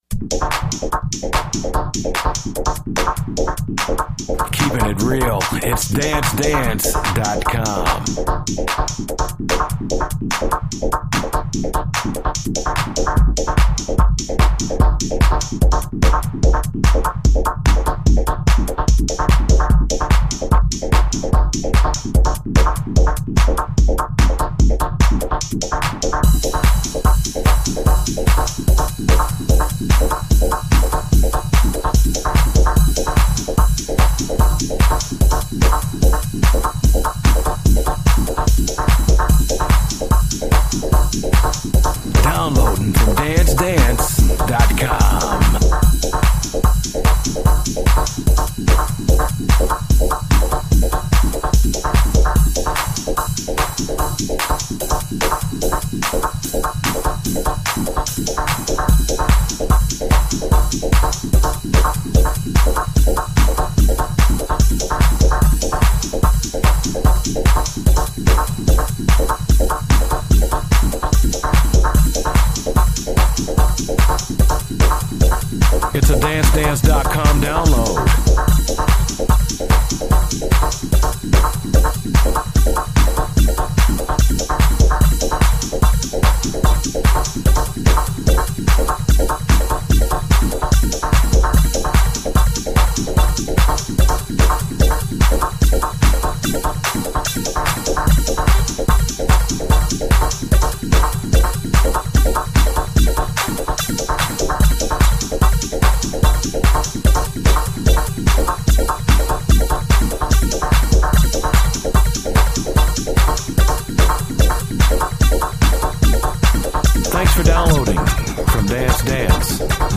reall smooth kinda electronic sounding